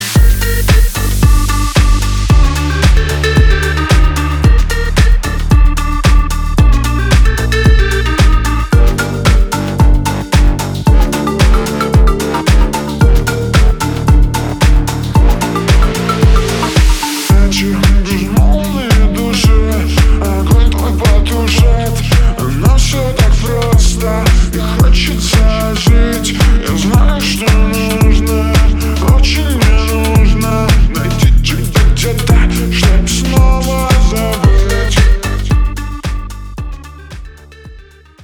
deep house
атмосферные
красивый мужской голос
мелодичные
спокойные
Electronica